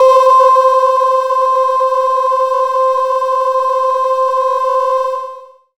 Amb1n2_g_synth_c3_ahhvoice.wav